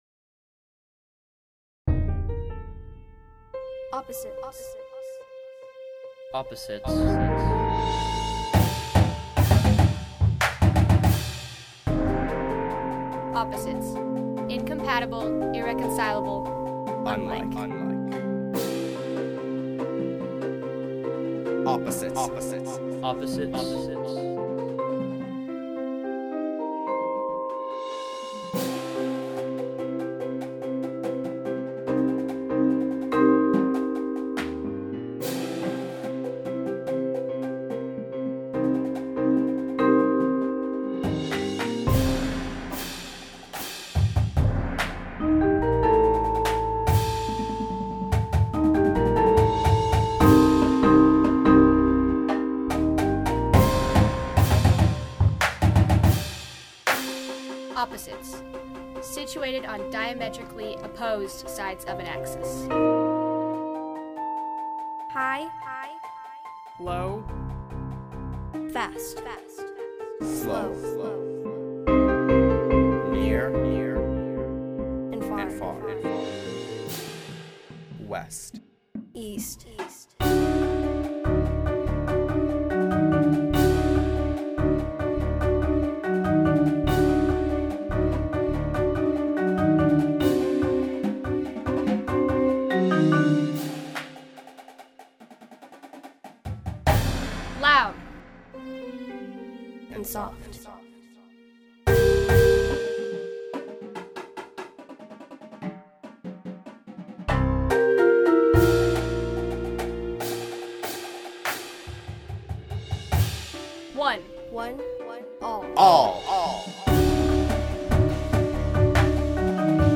• snare
• tenors (4 or 5)
• bass drum (3 to 5)
• xylo
• 4 marimba
• 2 synth